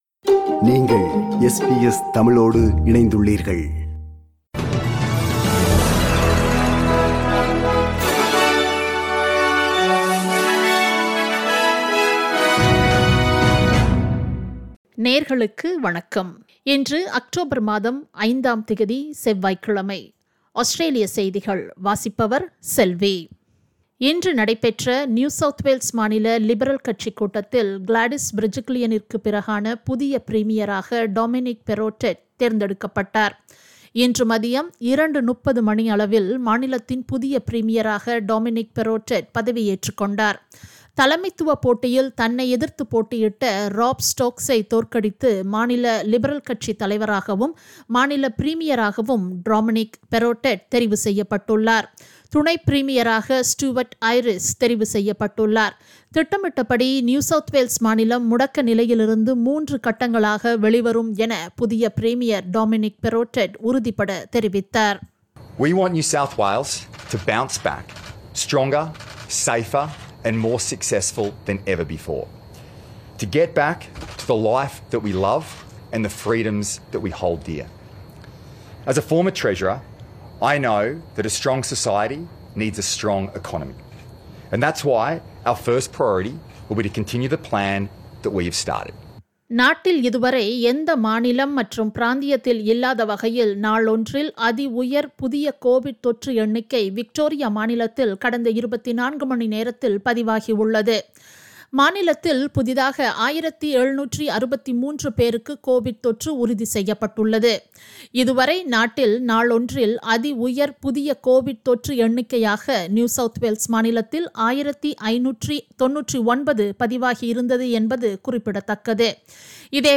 Australian news bulletin for Tuesday 05 October 2021.